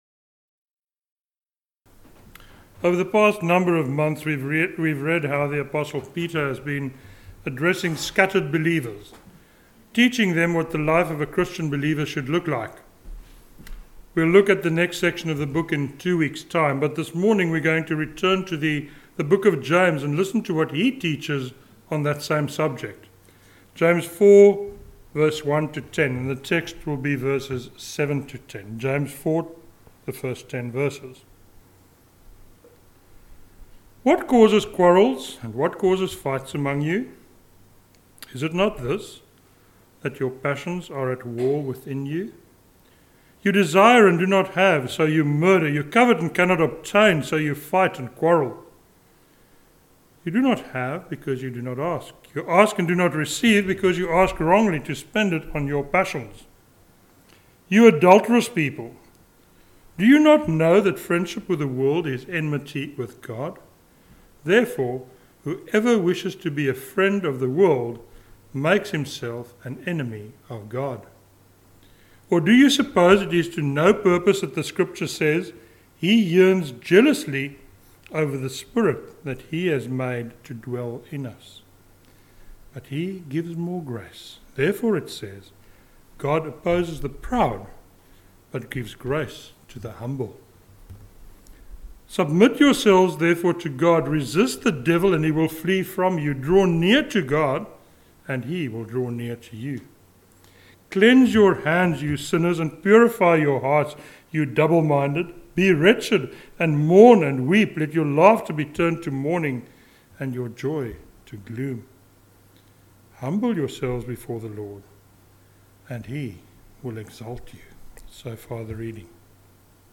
a sermon on James 4:7-10